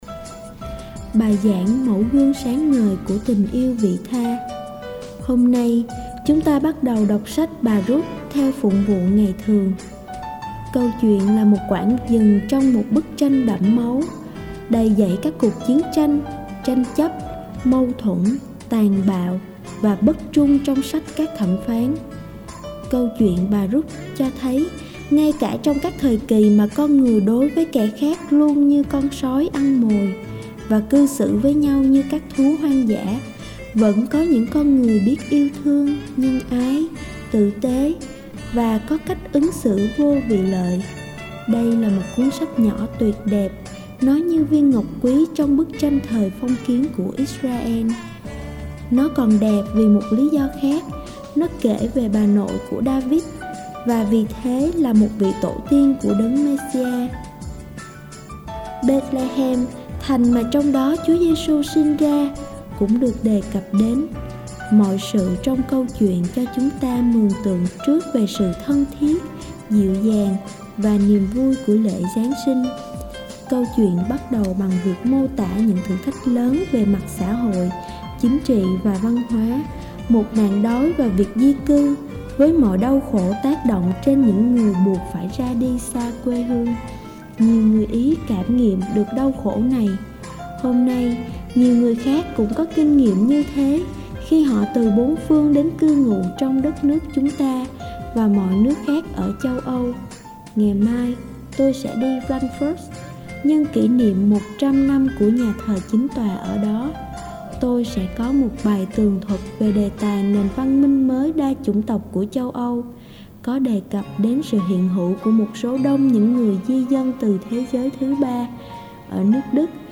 14. Bai giang mau guong sang ngoi cua tinh yeu vi tha.mp3